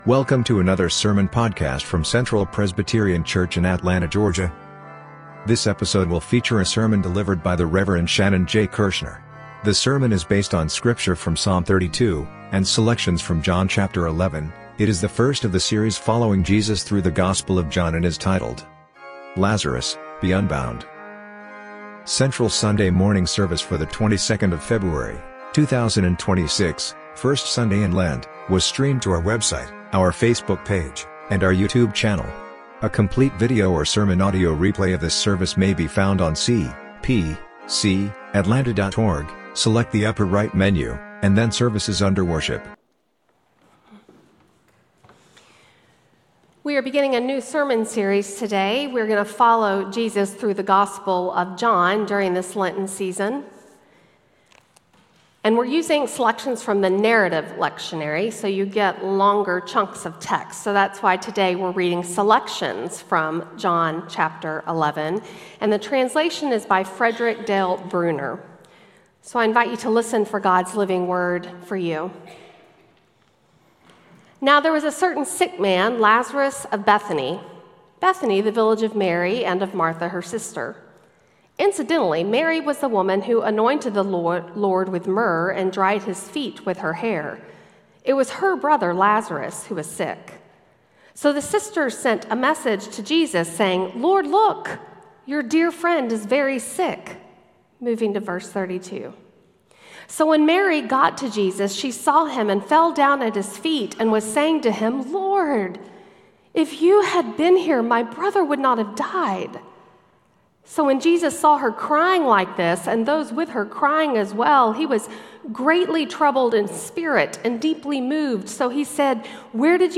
Sermon Audio: